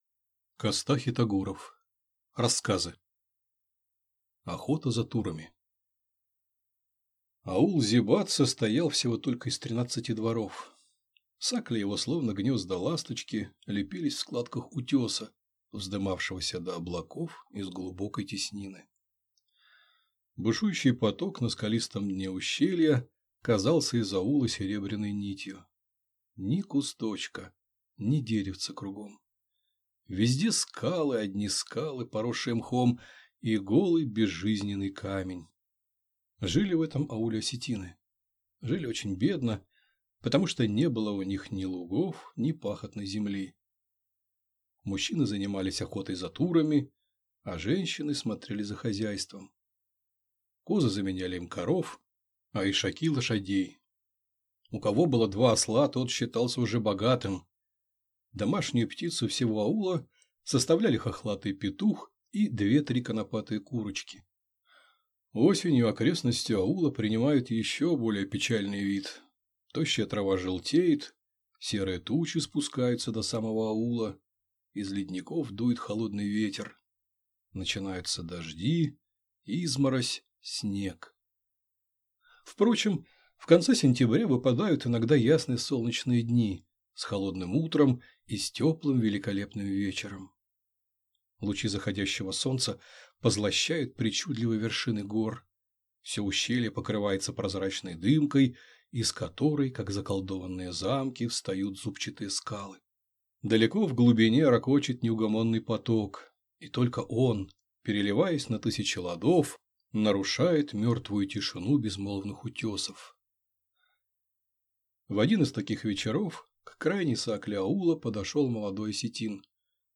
Аудиокнига Рассказы | Библиотека аудиокниг